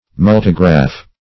Multigraph \Mul"ti*graph\, n. [Multi- + -graph.]